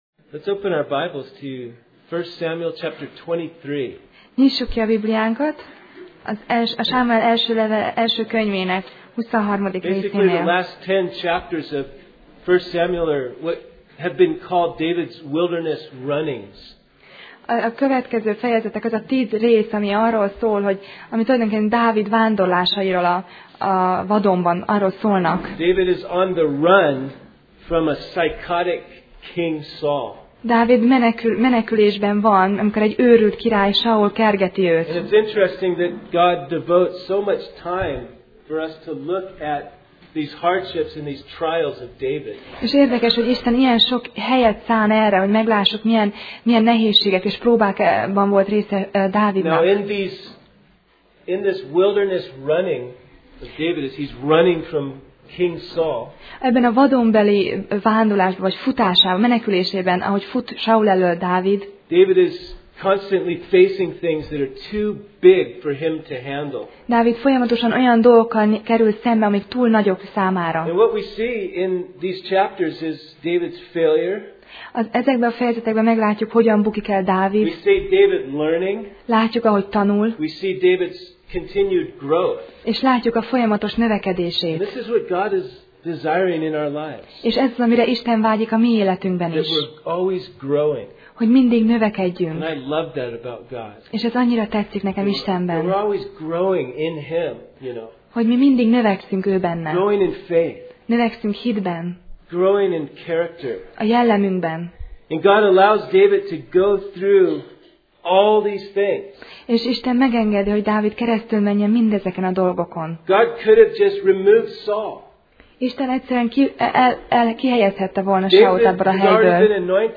Passage: 1Sámuel (1Samuel) 23:1-18 Alkalom: Szerda Este